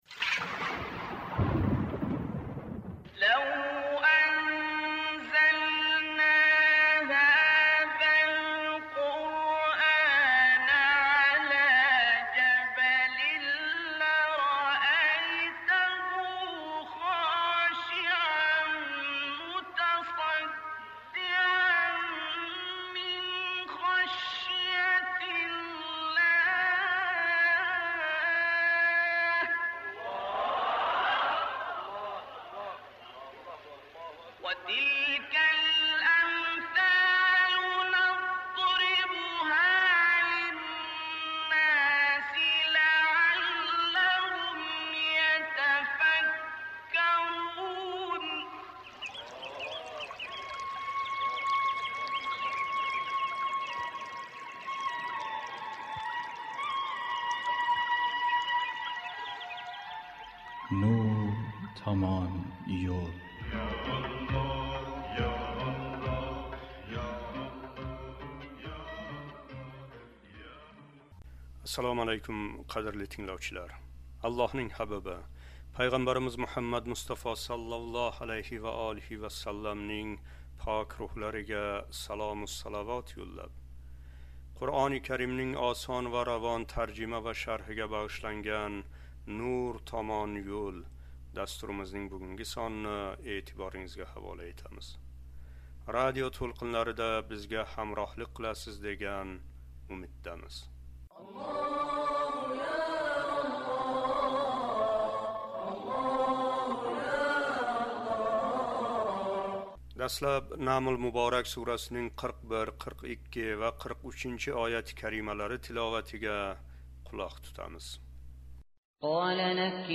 Дастлаб "Намл" муборак сурасининг 41-43-ояти карималари тиловатига қулоқ тутамиз: